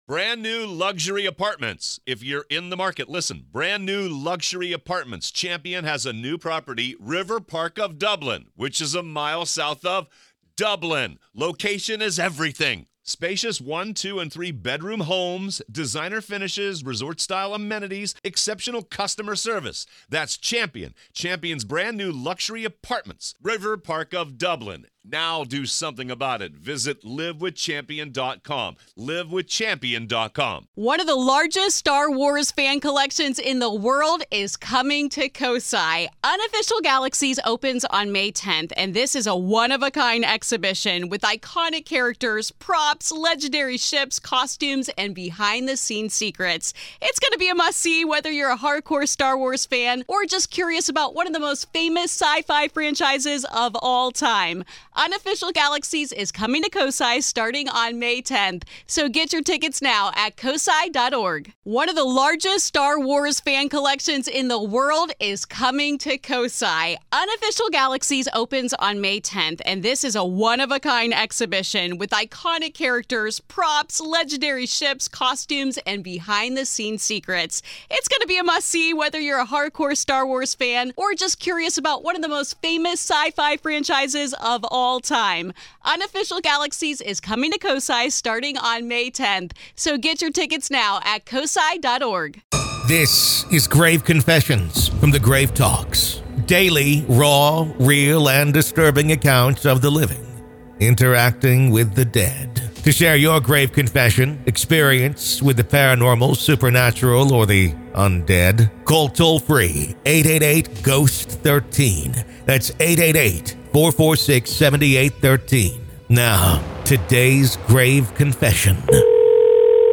This is a daily EXTRA from The Grave Talks. Grave Confessions is an extra daily dose of true paranormal ghost stories told by the people who survived them!